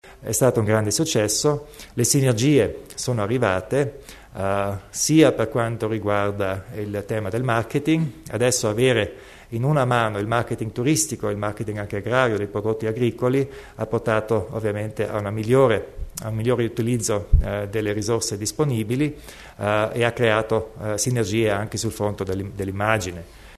Il Presidente Kompatscher illustra la riorganizzazione di IDM